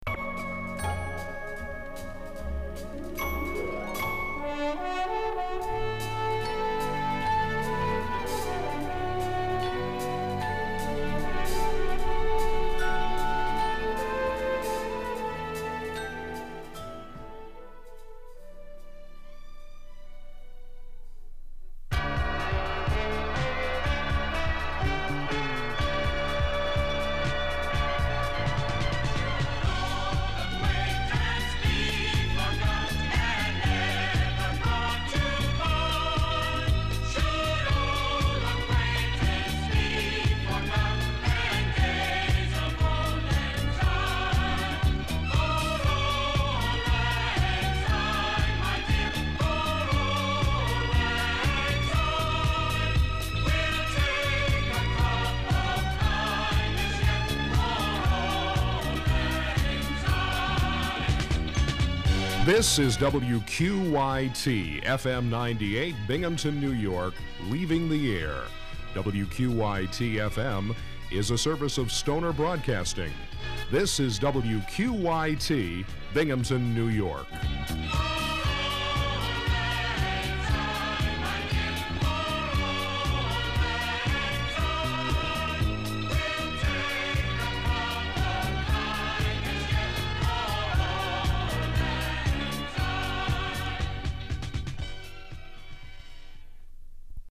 WQYT SIGN-OFF.mp3